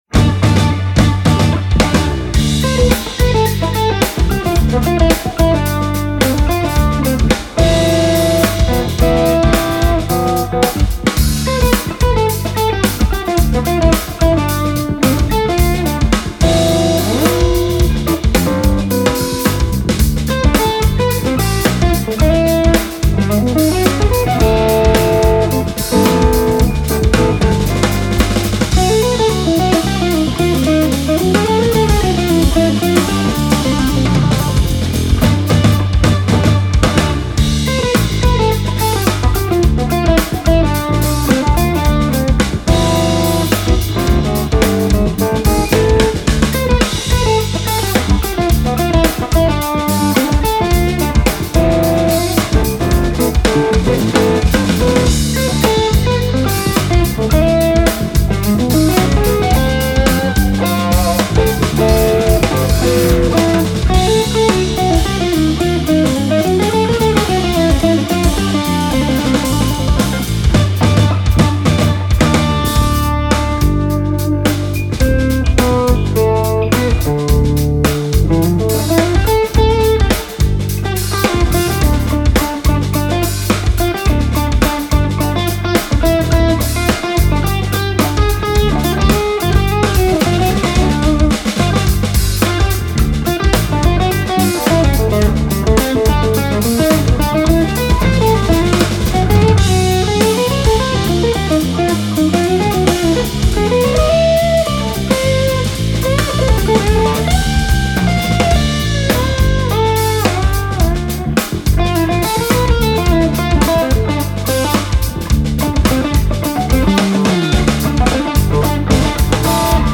Genere: Fusion.